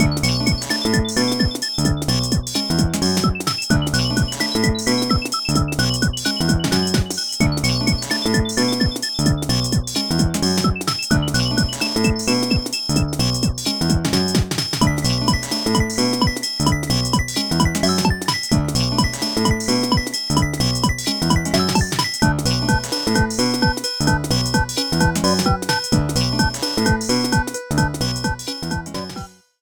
Clipped to 30 seconds and applied fade-out with Audacity